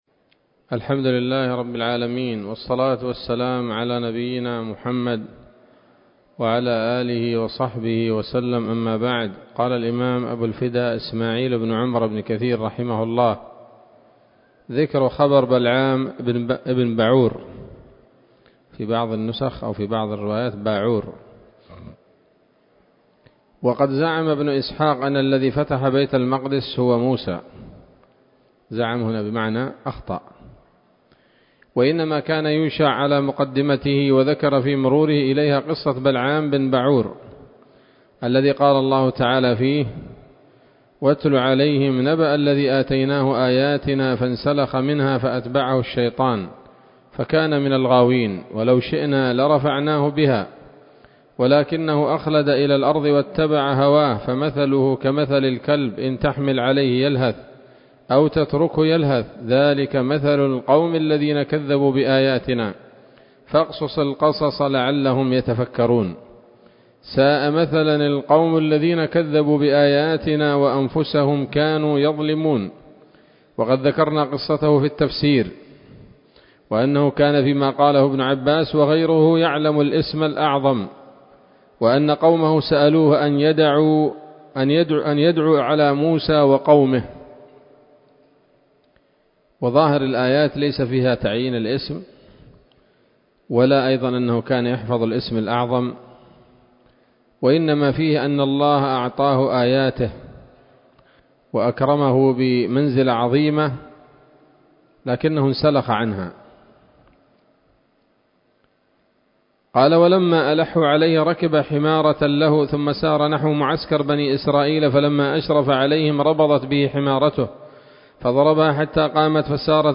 ‌‌الدرس الثامن بعد المائة من قصص الأنبياء لابن كثير رحمه الله تعالى